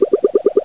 00102_Sound_Spin.mp3